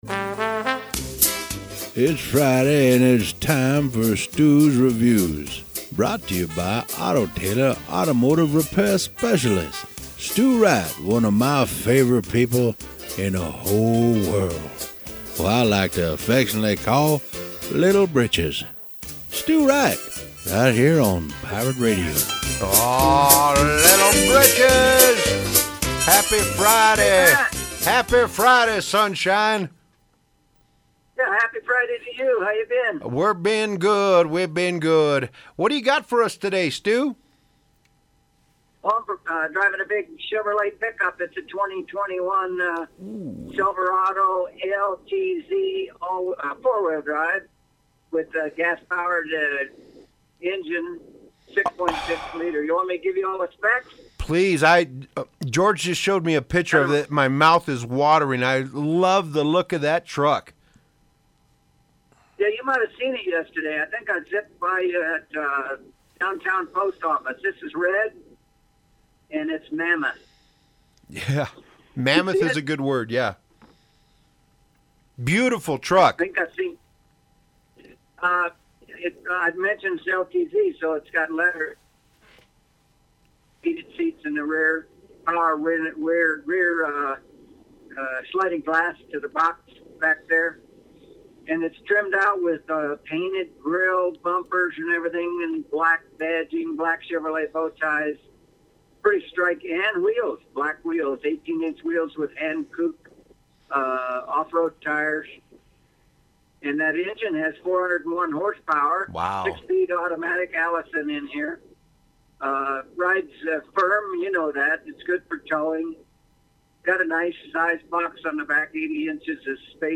Radio review